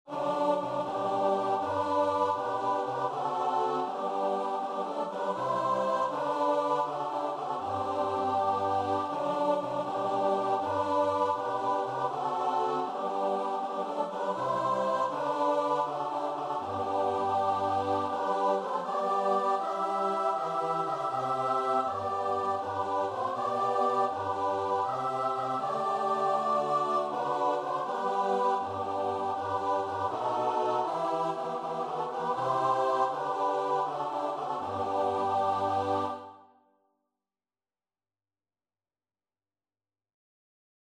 Christmas
9/8 (View more 9/8 Music)
Choir  (View more Easy Choir Music)